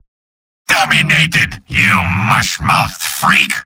Robot-filtered lines from MvM. This is an audio clip from the game Team Fortress 2 .
{{AudioTF2}} Category:Spy Robot audio responses You cannot overwrite this file.